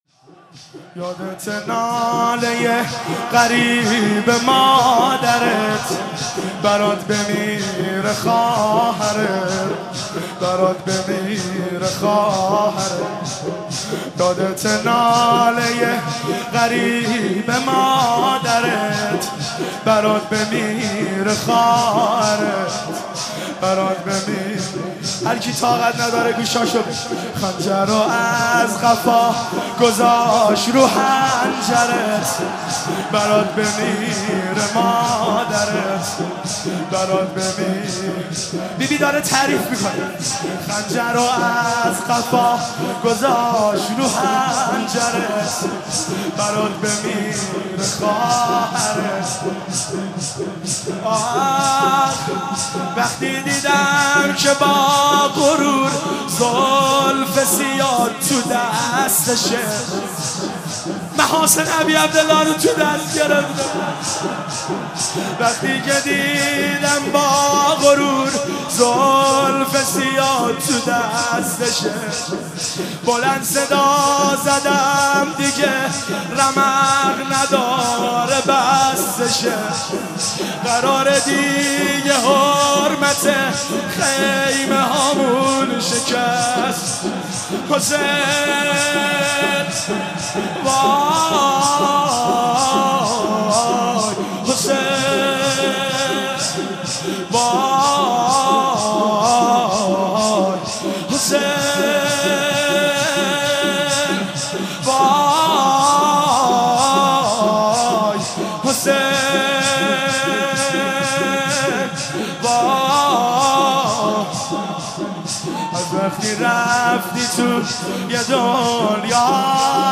مداحی یادته ناله غریب مادرت(شور) عاشورا محرم 1392 هیئت خادم الرضا(ع) قم
مداحی یادته ناله غریب مادرت(شور)